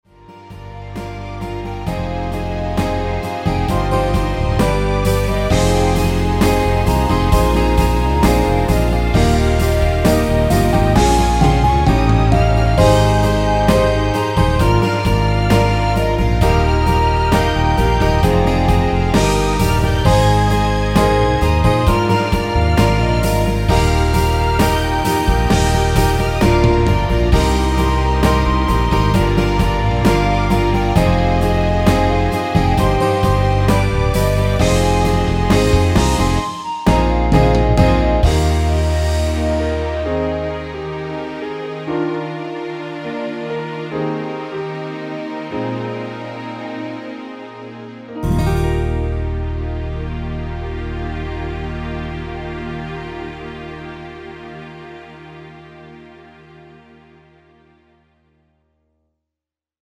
원키에서(+5)올린 멜로디 포함된 (짧은편곡) MR입니다.
Db
앞부분30초, 뒷부분30초씩 편집해서 올려 드리고 있습니다.
(멜로디 MR)은 가이드 멜로디가 포함된 MR 입니다.